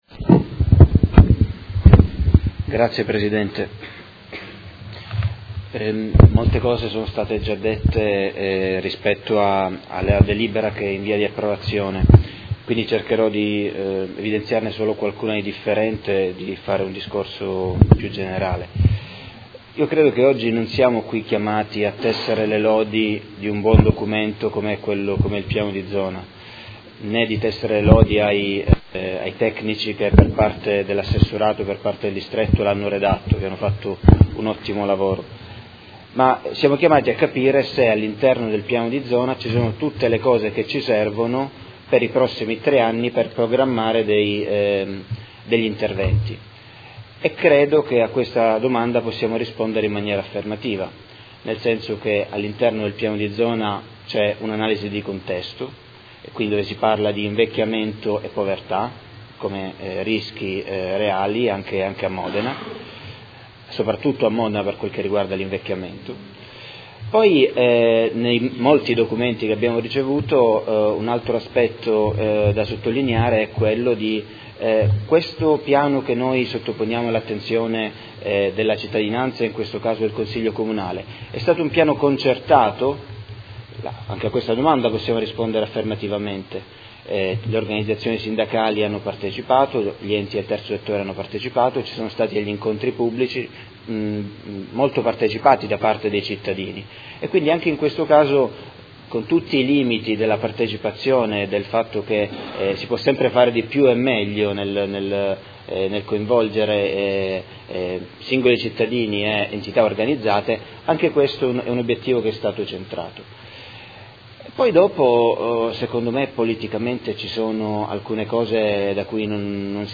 Tommaso Fasano — Sito Audio Consiglio Comunale
Dichiarazione di voto su proposta di deliberazione: Approvazione dell’Accordo di Programma fra Comune di Modena ed Azienda USL di Modena avente ad oggetto il Piano di Zona Triennale 2018-2010 per la Salute e il Benessere sociale